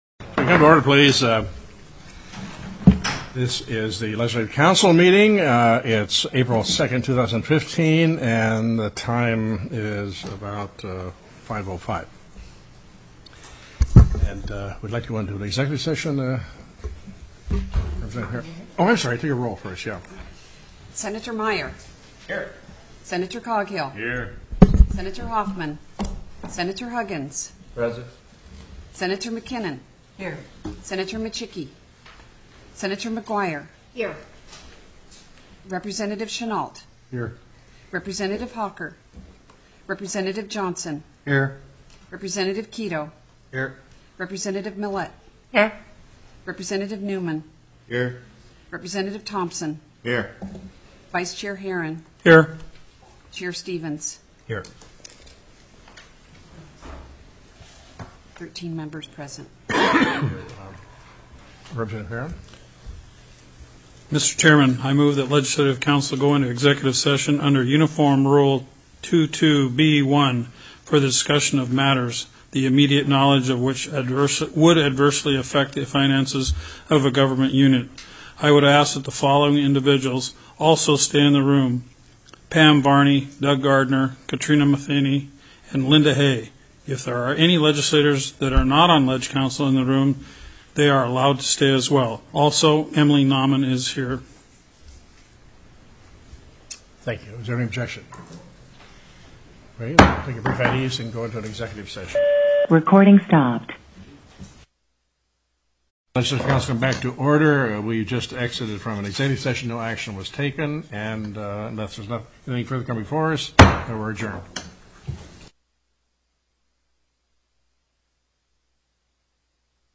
The audio recordings are captured by our records offices as the official record of the meeting and will have more accurate timestamps.
to order at  5:05 p.m. in Room 519 (House Finance) of the